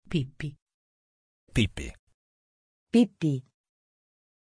Pronunciation of Pippi
pronunciation-pippi-it.mp3